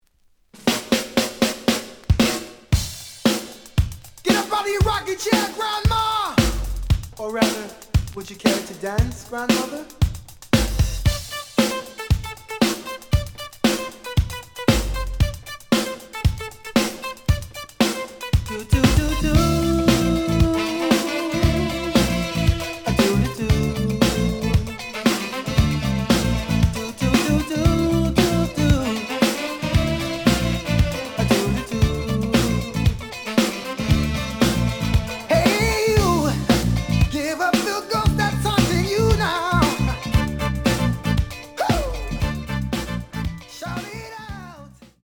The audio sample is recorded from the actual item.
●Genre: Soul, 80's / 90's Soul
Slight edge warp. But doesn't affect playing. Plays good.